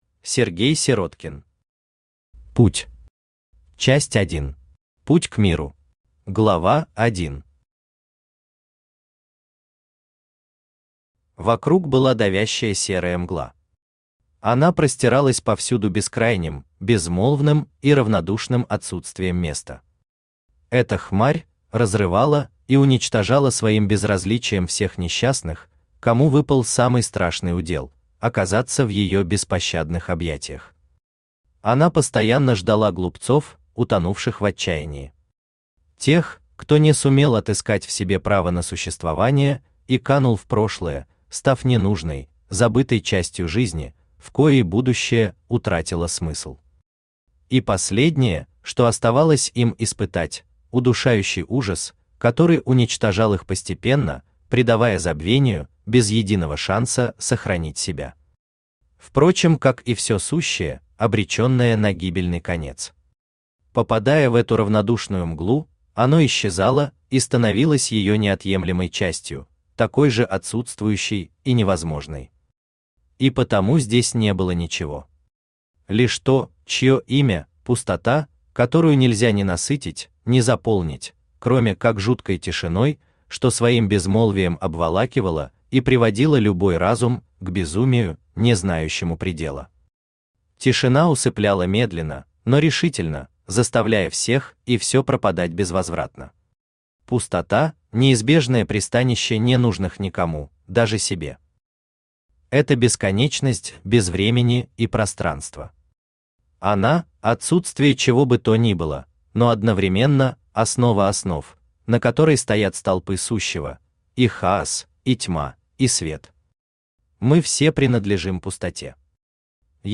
Aудиокнига Путь.Часть1. Путь к Миру Автор Сергей Павлович Сироткин Читает аудиокнигу Авточтец ЛитРес.